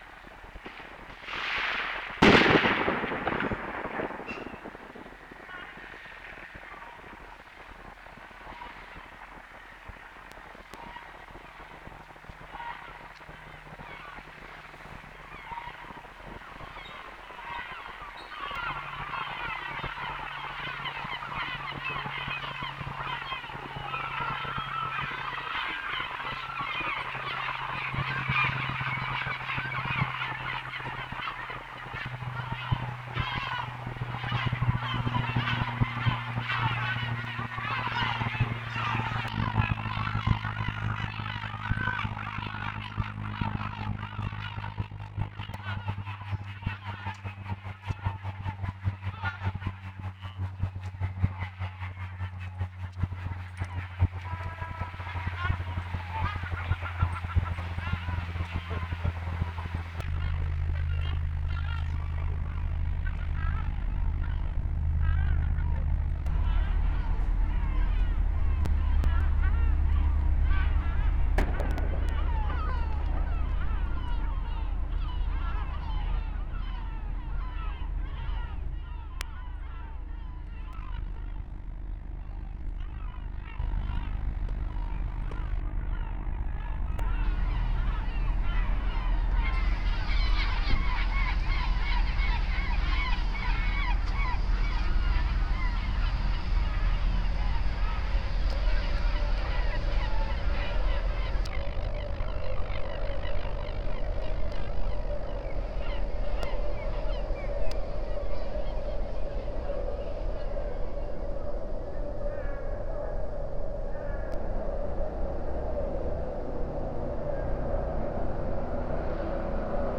eenden en vuurwerk in binckhorst
eenden-en-vuurwerk-in-binckhorst.wav